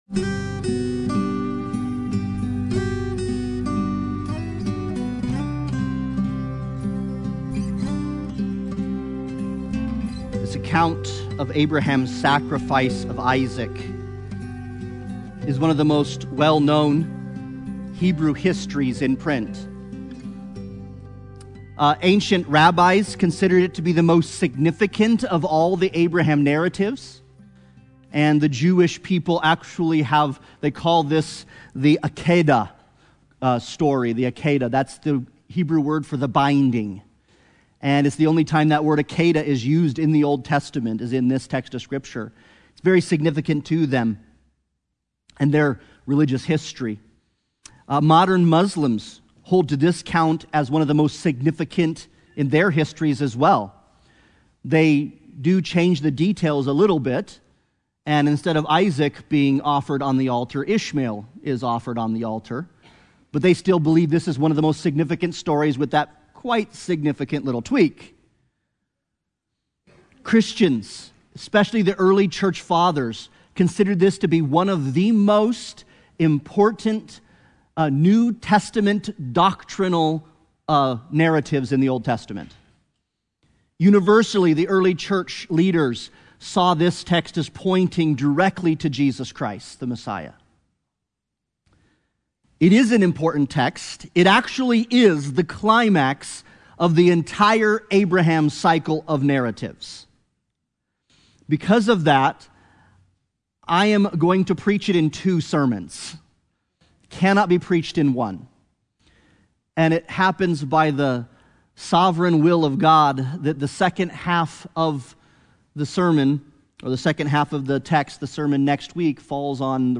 Genesis 22:1-19 Service Type: Sunday Morning Worship « Human Suffering